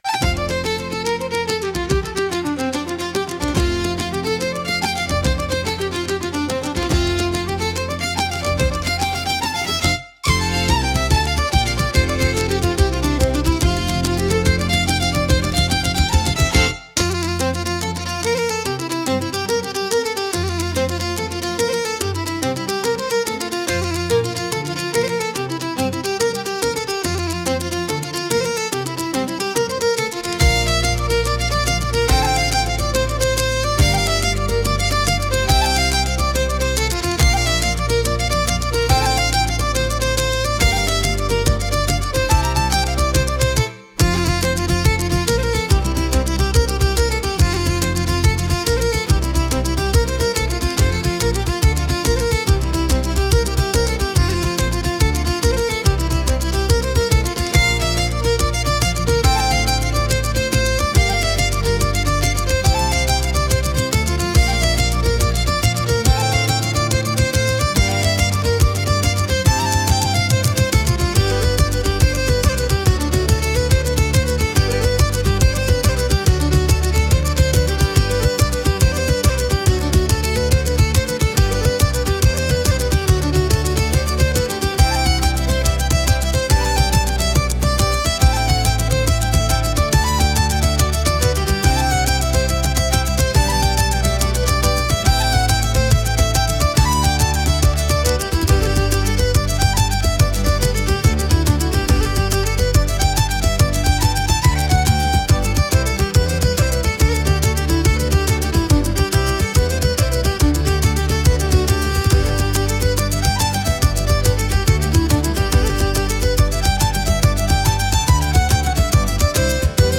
神秘的で豊かな民族色があり、物語性や異国情緒を演出するシーンに効果的なジャンルです。